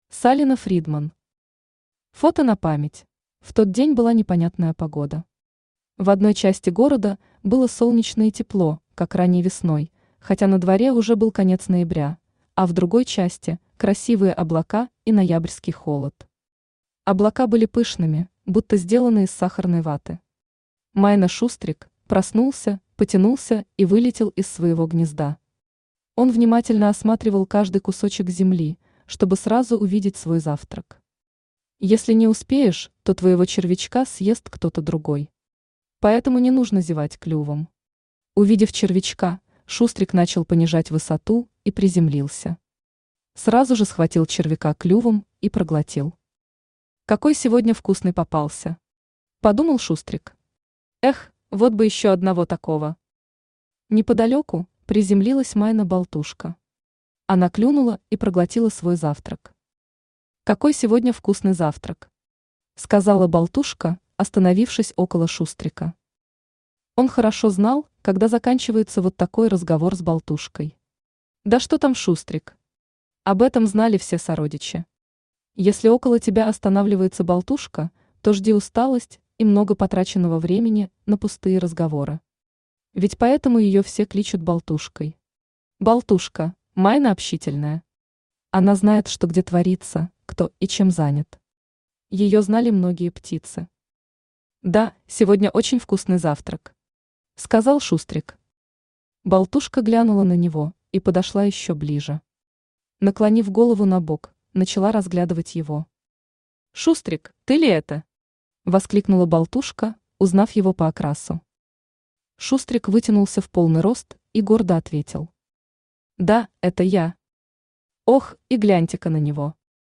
Aудиокнига Фото на память Автор Салина Фридман Читает аудиокнигу Авточтец ЛитРес.